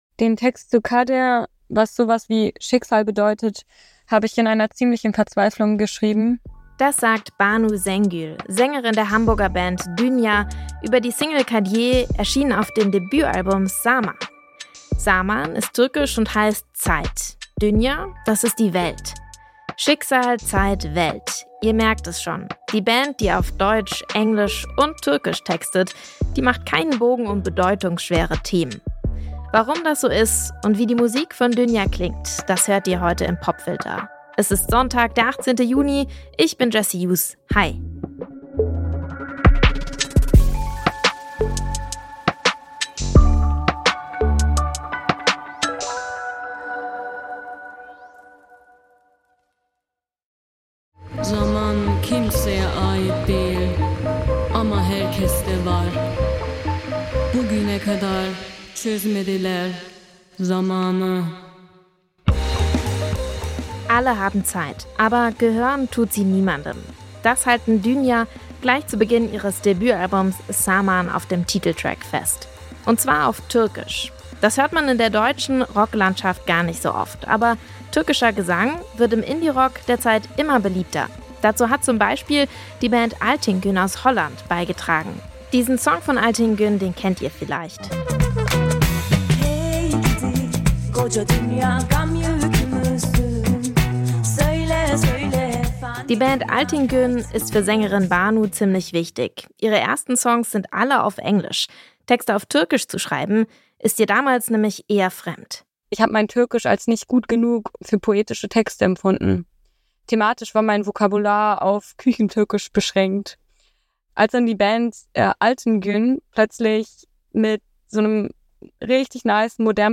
Die Hamburger Newcomer-Band gießt ihre Beobachtungen in fuzzy Psych-Rock und hat gerade das Debütalbum „Zaman“ veröffentlicht. Darauf findet man auch den Song „Kader“, in dem es ums Schicksal geht.